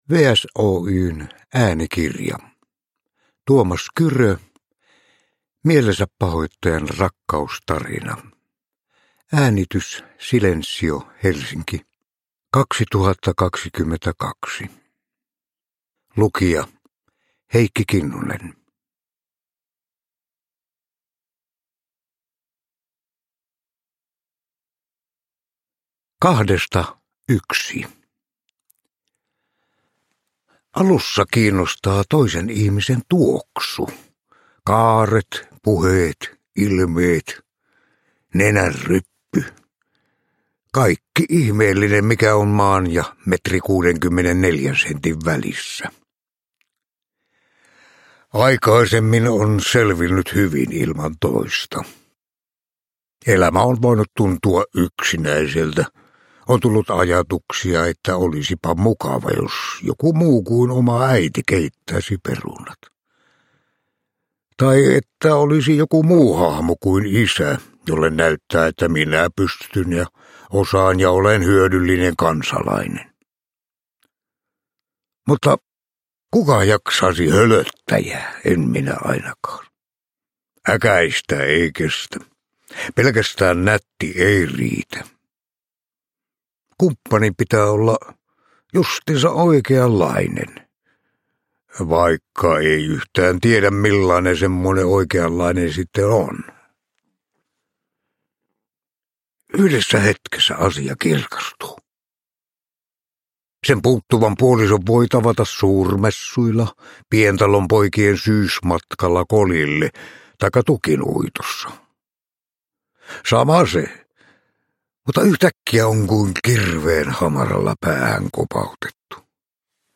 Mielensäpahoittajan rakkaustarina – Ljudbok
Uppläsare: Heikki Kinnunen